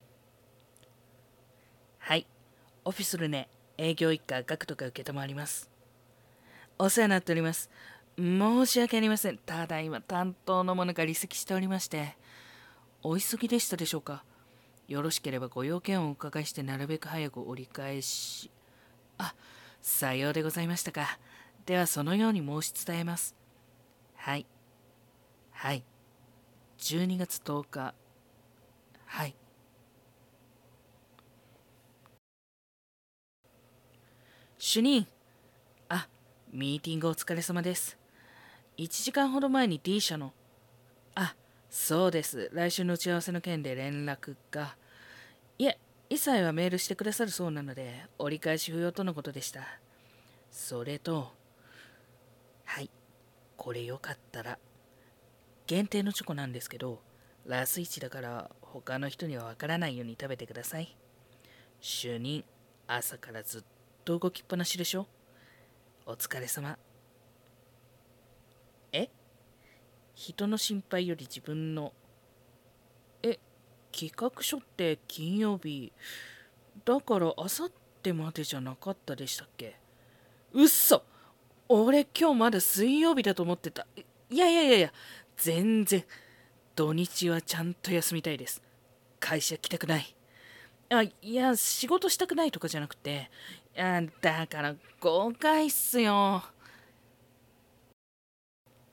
声劇